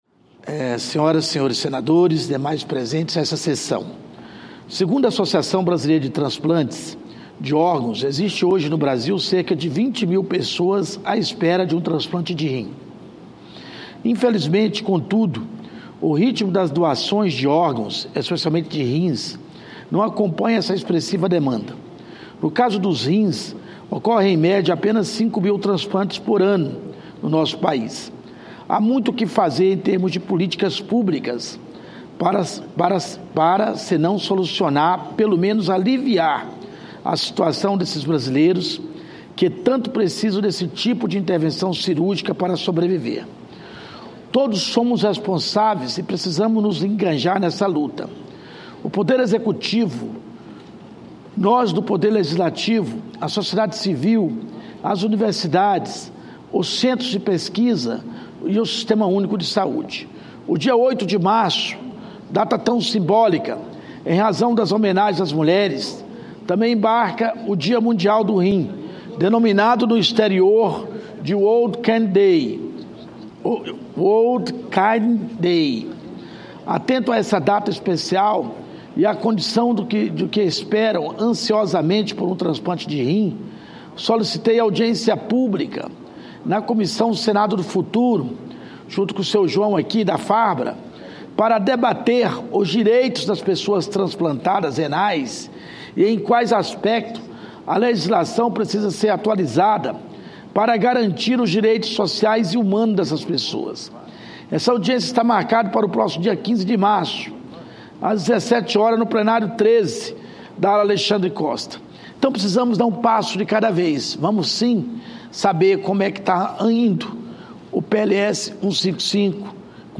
Sessão especial
Pronunciamento do senador Hélio José